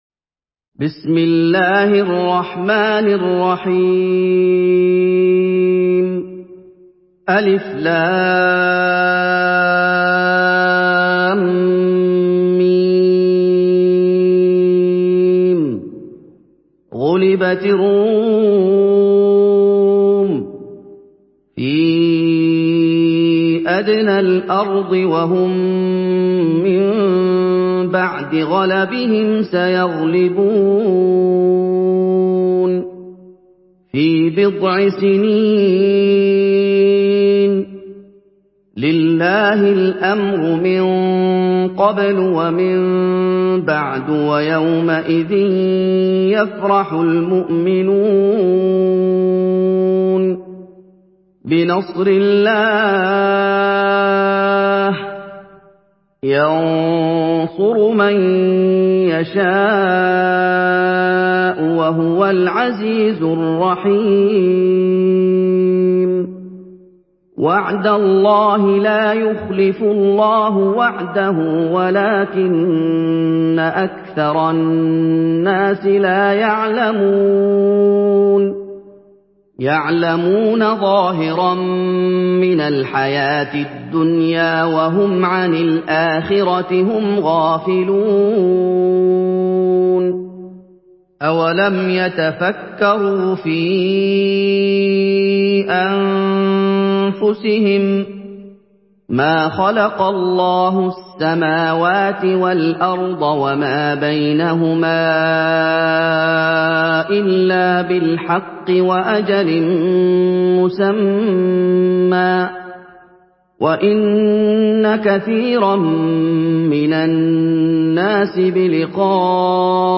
Surah Ar-Rum MP3 by Muhammad Ayoub in Hafs An Asim narration.
Murattal Hafs An Asim